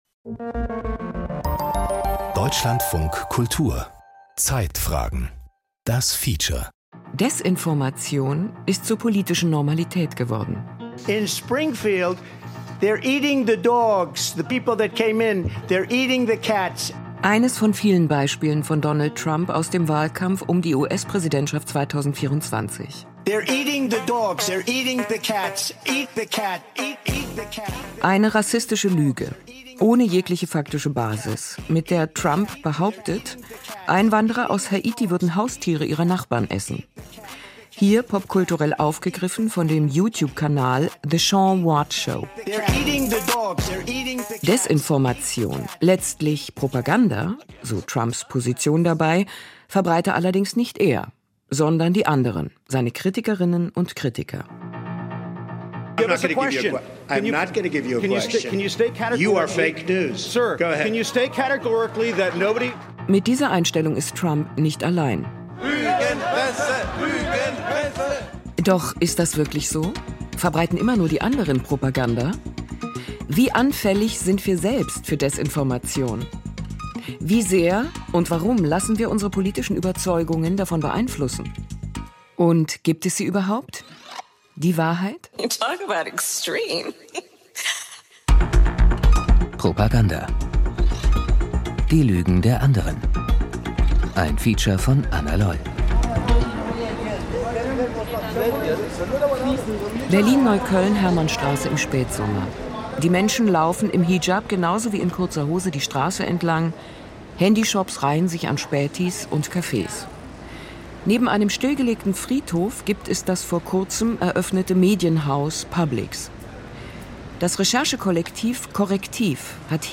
Ein Feature über die Geschichte von Macht und Diskurs.